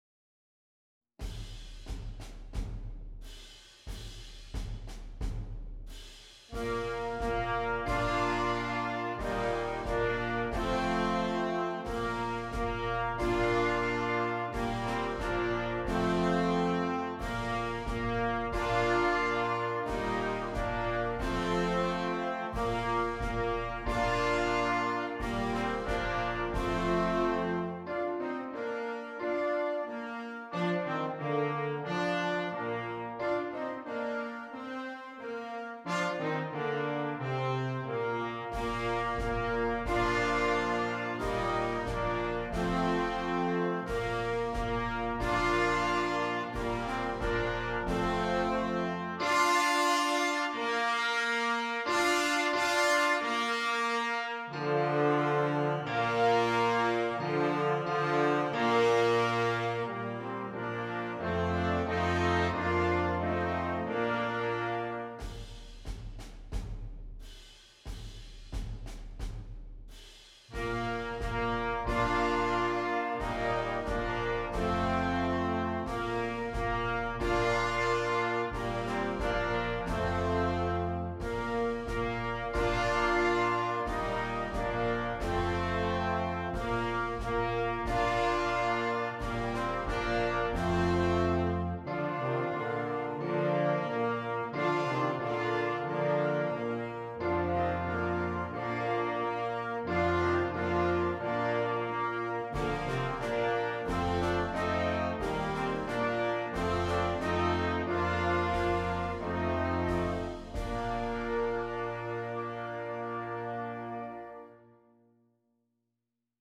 for Wind Band